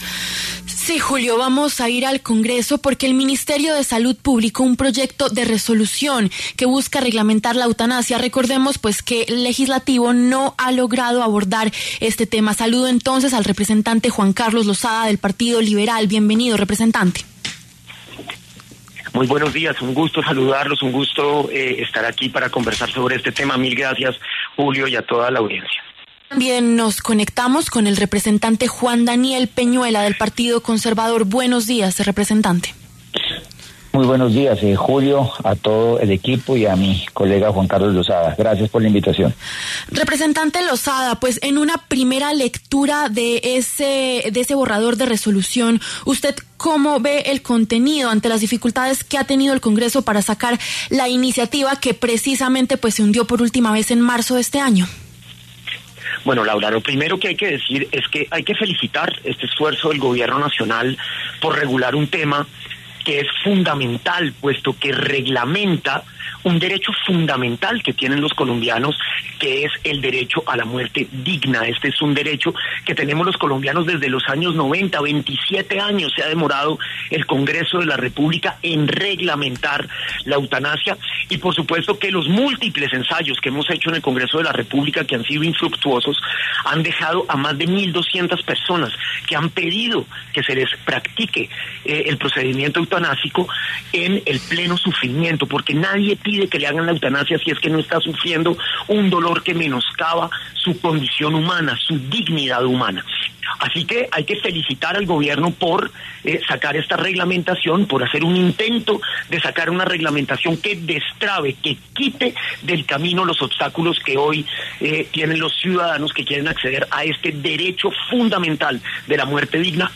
Los representantes Juan Carlos Lozada, del Partido Liberal, y Juan Daniel Peñuela, del Partido Conservador, pasaron por los micrófonos de La W.